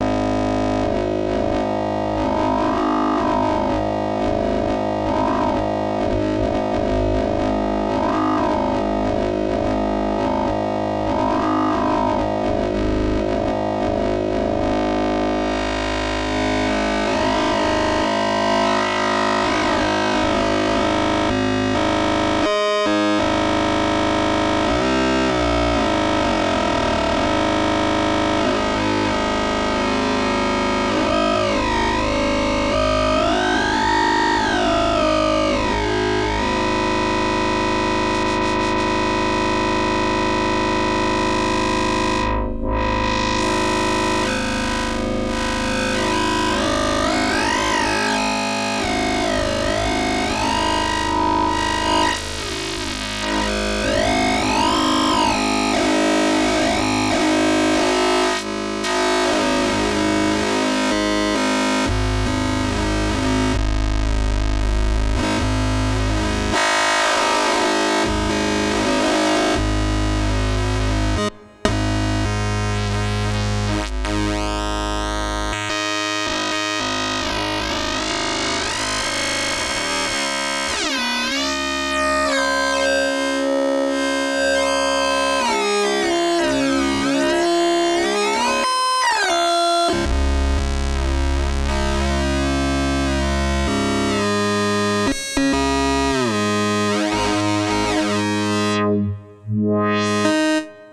recorded an example of some of the wild pulse-width Osc Sync + Fm sounds between osc1 & 2 with a little filter fm added in the 2nd half: